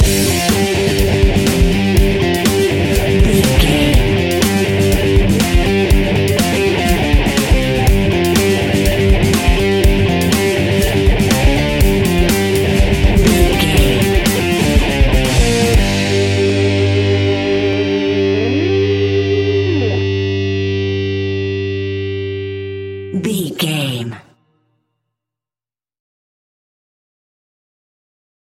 Aeolian/Minor
hard rock
blues rock
distortion
rock instrumentals
Rock Bass
Rock Drums
distorted guitars
hammond organ